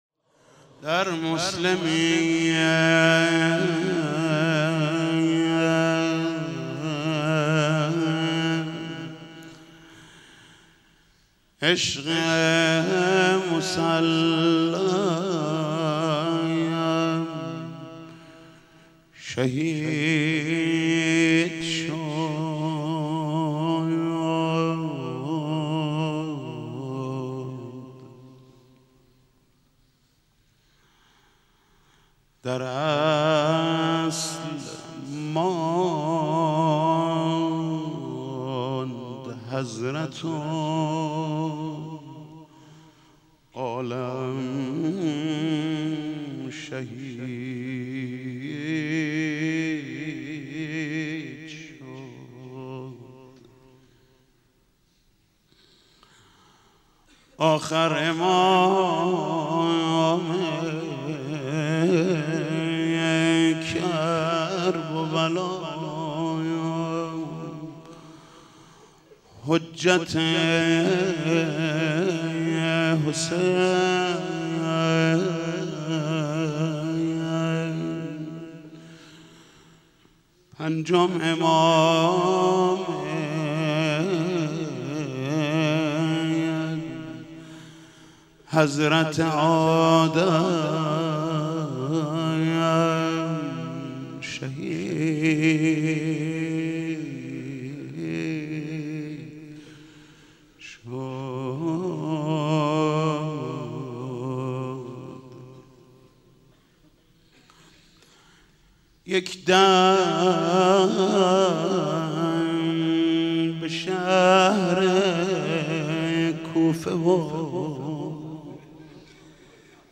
شهادت حضرت امام محمد باقر علیه السلام 1393 | مسجد حضرت امیر | حاج محمود کریمی
در مسلمیه عشق مسلّم شهید شد | روضه | حضرت امام محمد باقر و حضرت مسلم علیهما سلام